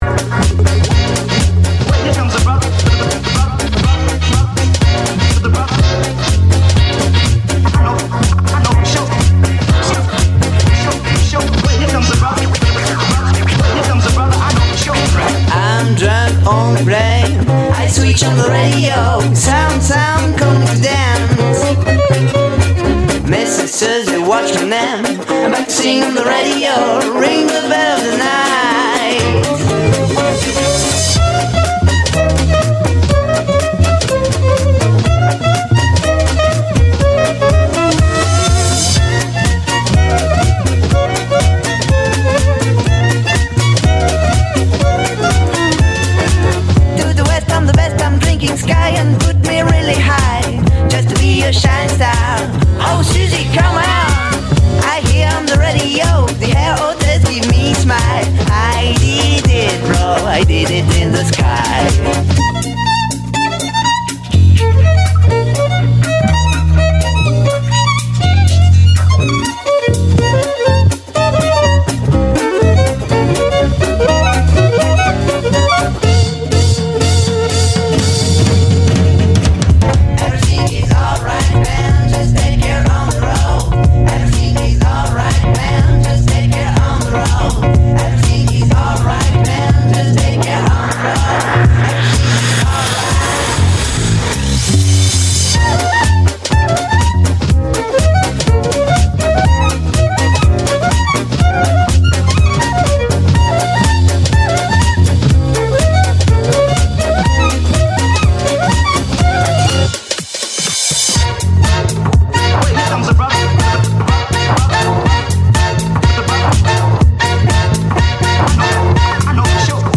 Electro Swing, Jazzy House, Funky Beats DJ for Hire